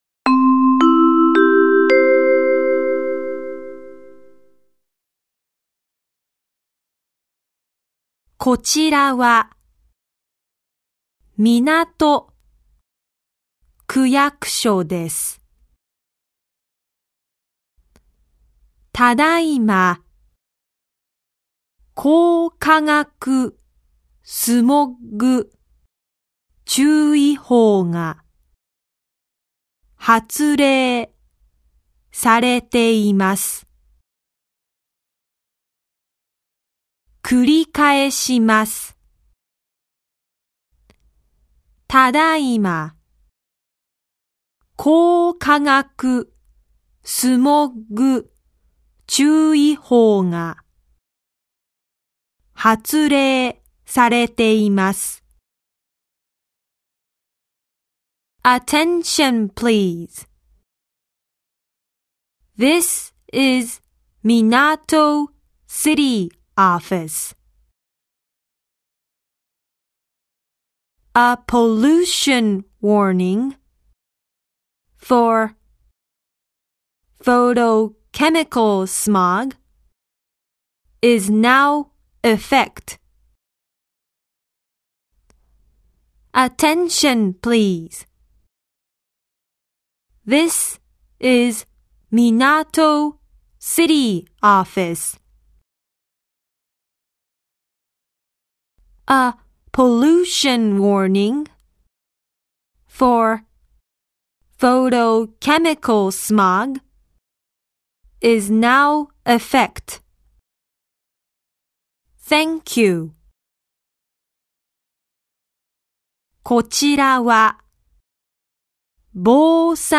港区防災行政無線音声
防災行政無線は緊急情報等を広範囲に伝えるため、必要な音量で放送しています。